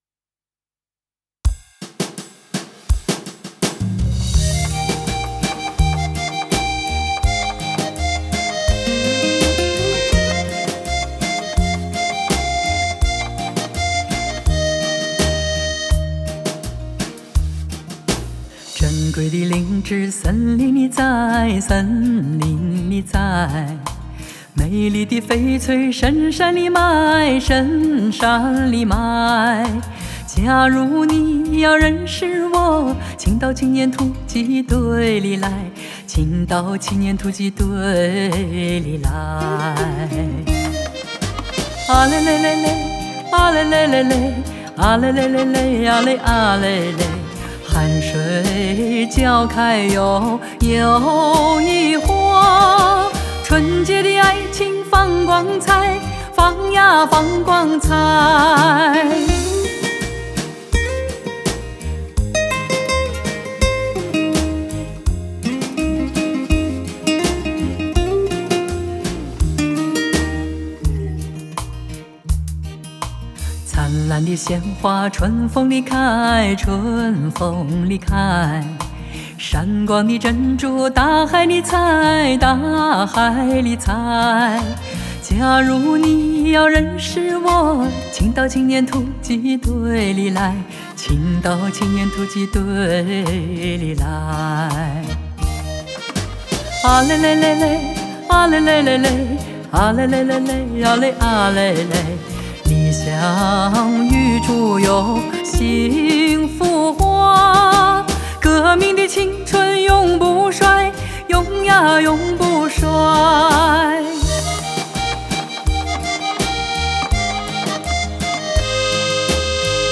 Ps：在线试听为压缩音质节选，体验无损音质请下载完整版 https